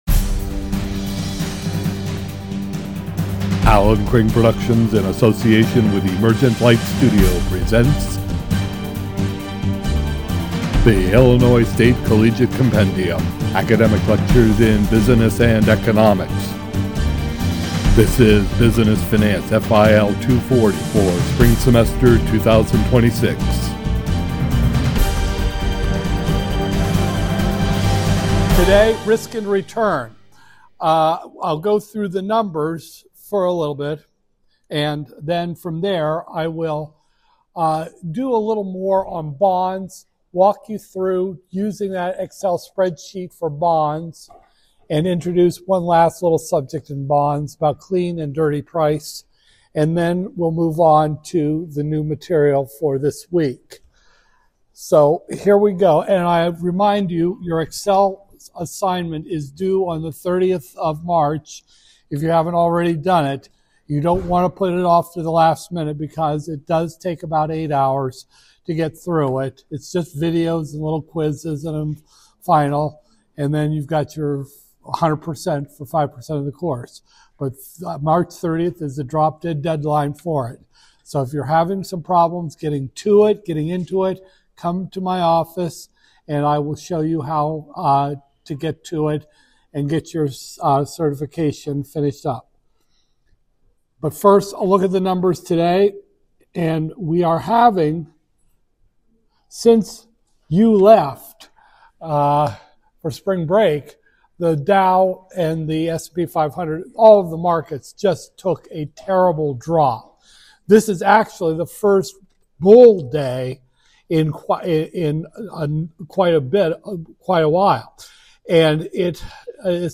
Business Finance, FIL 240-002, Spring 2026, Lecture 15